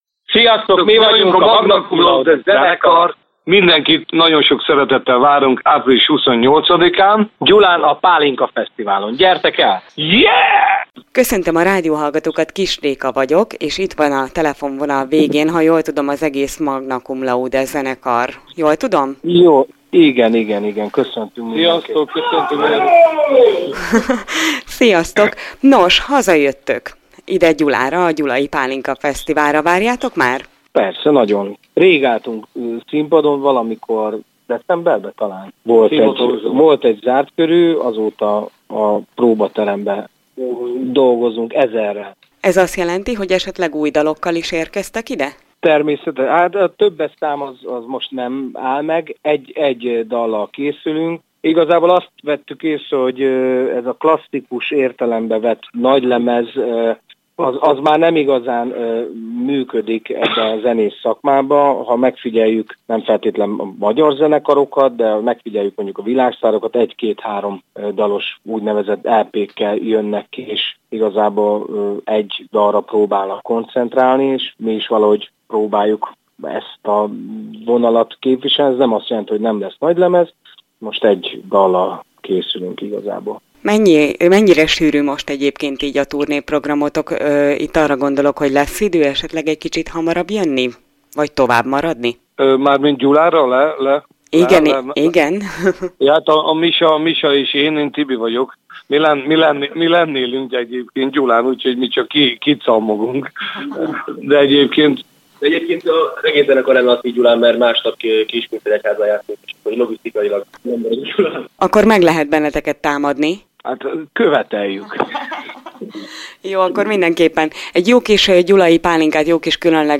Magna Cum Laude a Gyulai Pálinkafesztiválon. Interjú a zenekarral, akik teljes létszámban várták tudósítónk hívását - Körös Hírcentrum